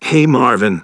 synthetic-wakewords
ovos-tts-plugin-deepponies_Nameless Hero_en.wav